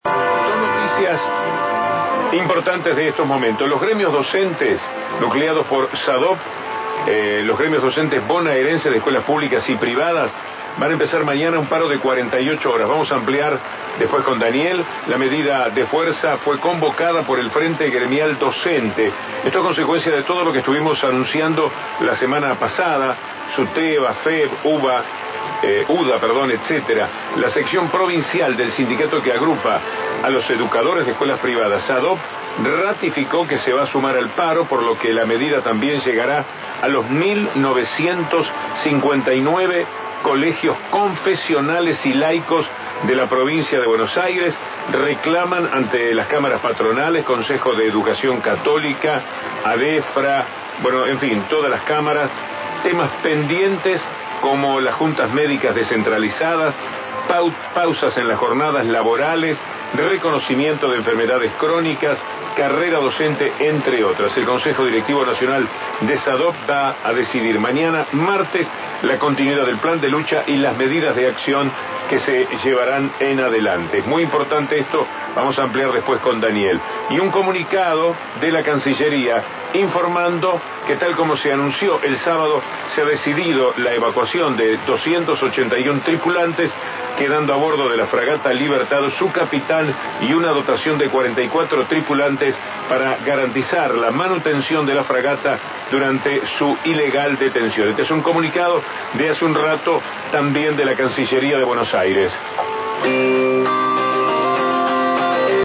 Información brindada por Víctor Hugo Morales